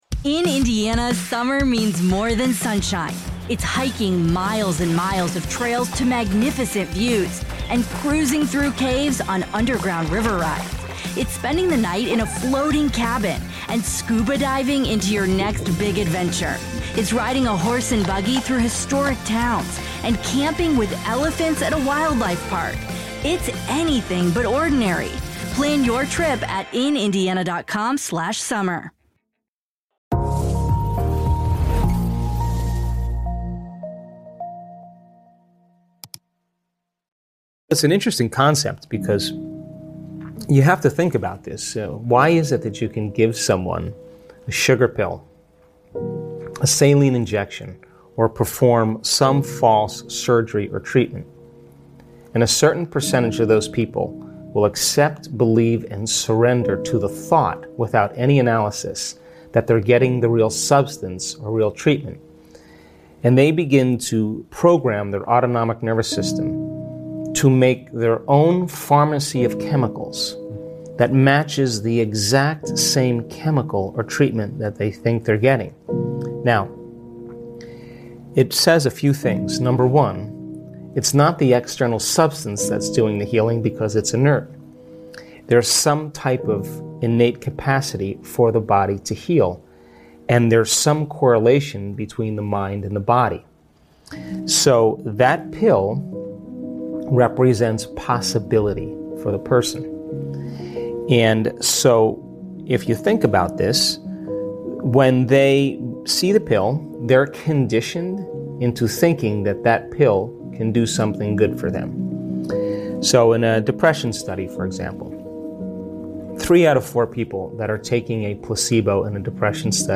1 Belief Systems and Their Impact on Health - Powerful Motivational Speech 11:06